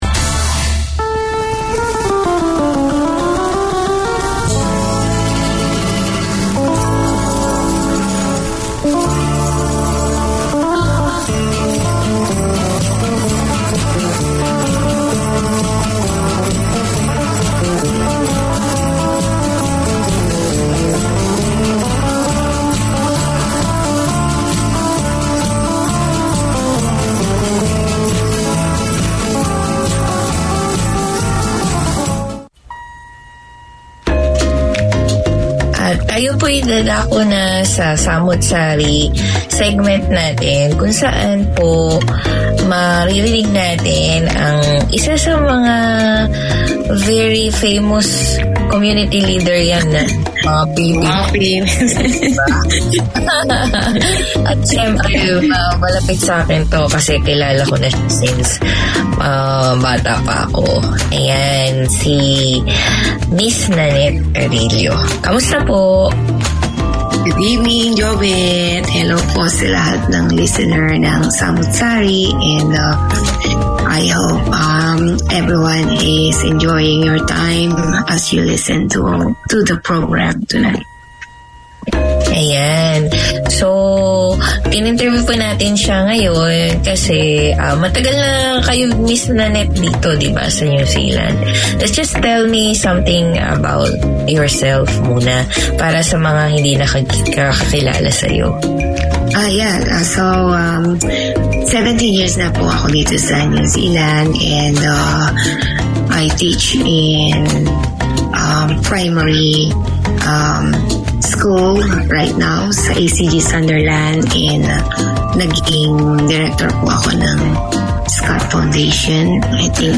Folk, ethnic and original Filipino music, Philippines news, community bulletins, sports updates, interviews, opinions and discussions seasoned with Filipino showbiz news.